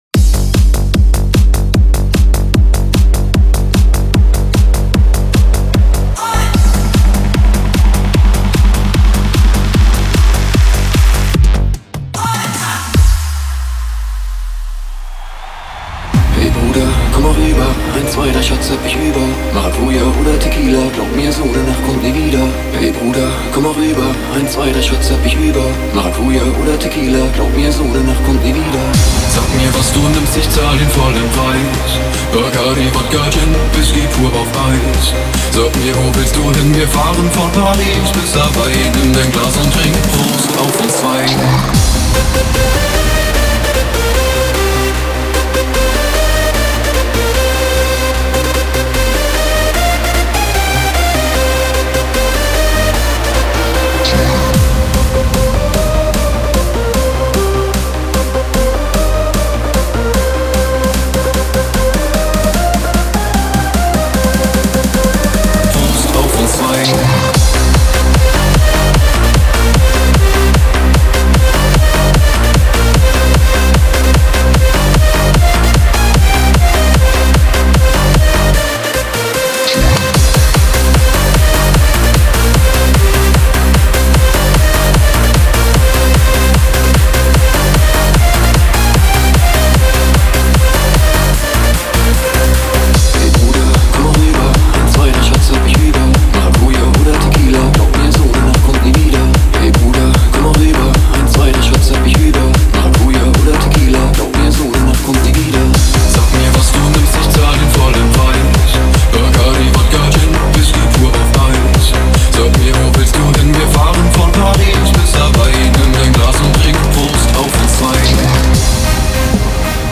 Hands Up song